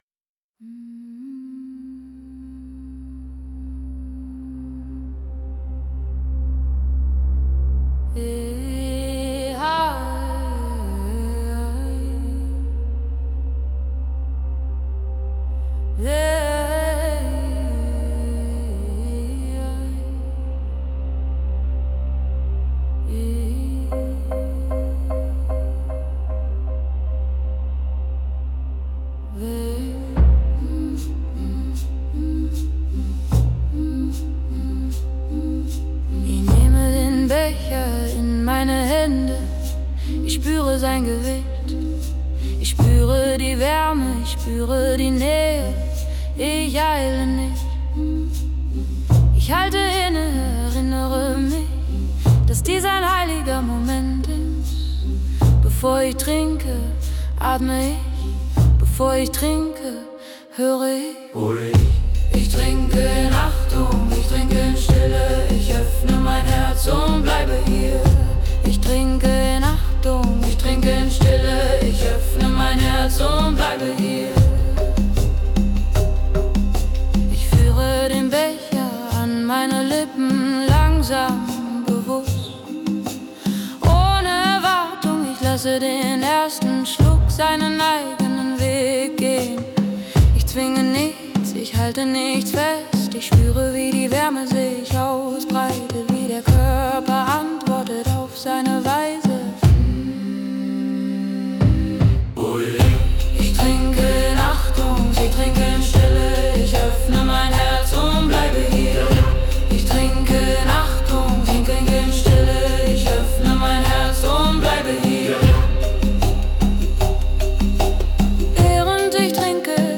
Die Lieder sind lang, ruhig, repetitiv und bewusst schlicht.
• klare Sprache, langsamer Puls